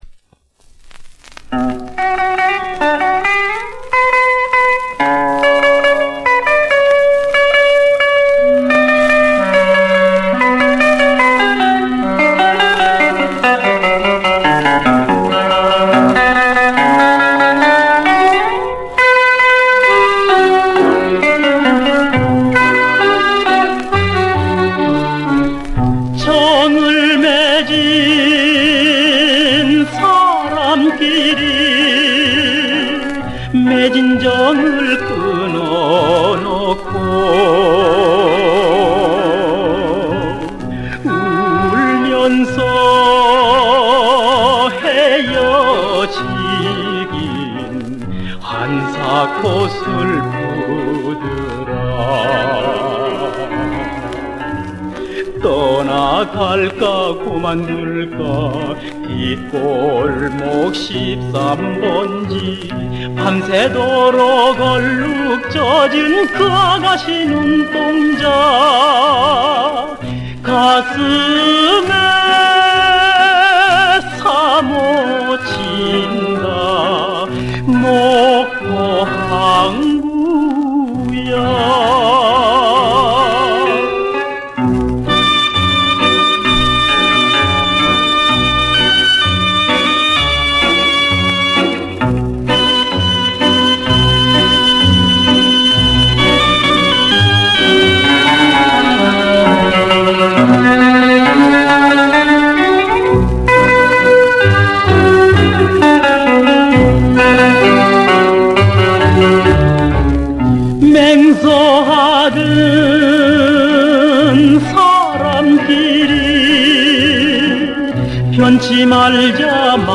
♠그때 그 시절 옛 가요/★50~60년(측음기)